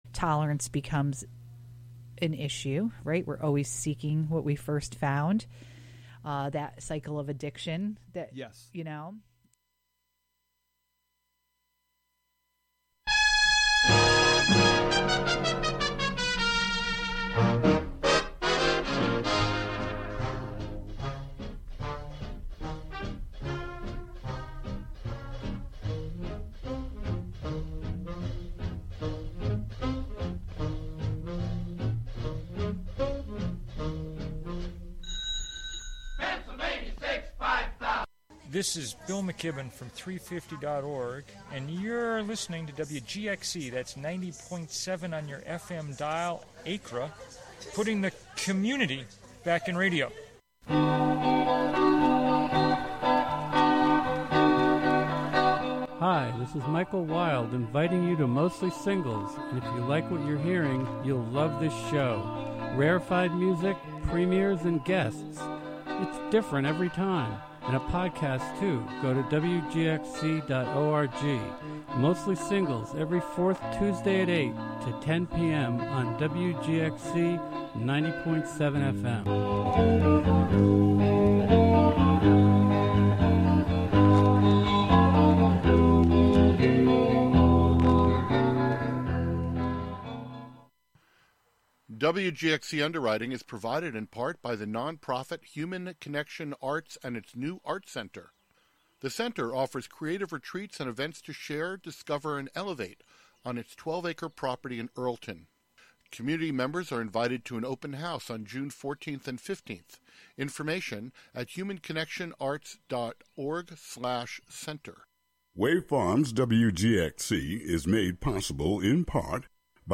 in the studio
interviews